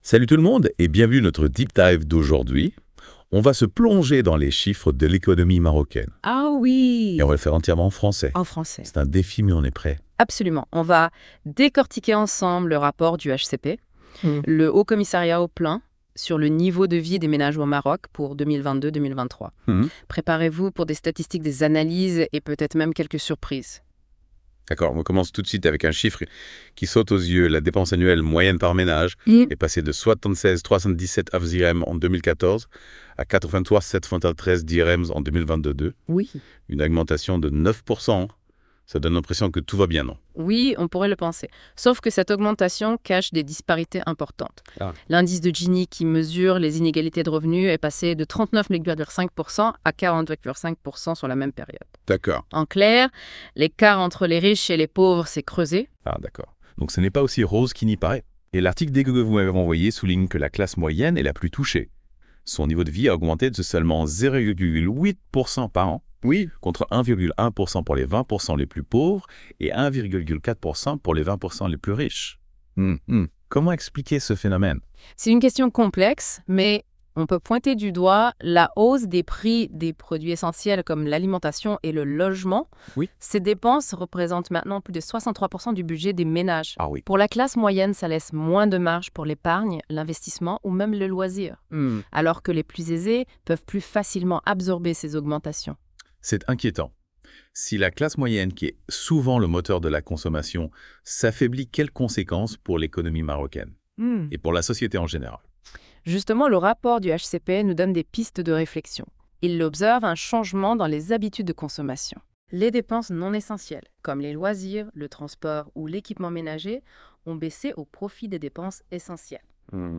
Débat (30.65 Mo) Comment l'évolution socio-économique au Maroc impacte-t-elle les différentes classes sociales en 2025 ?